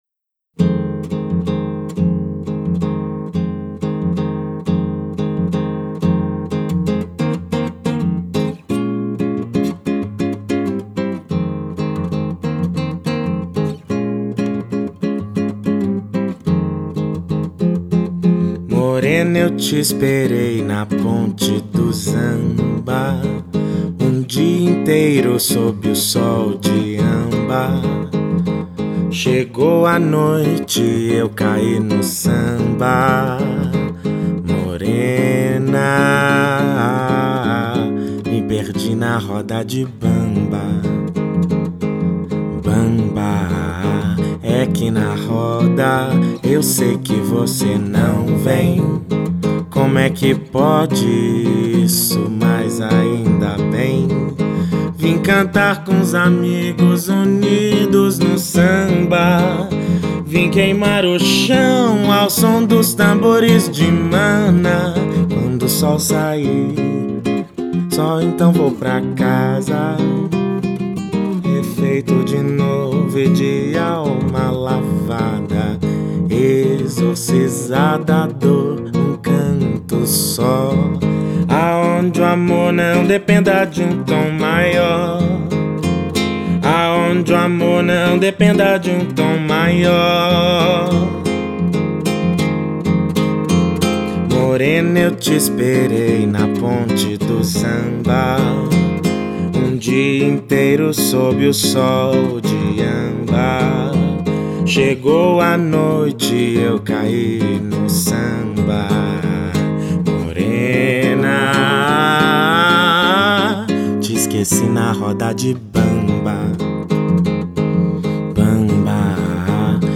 EstiloMPB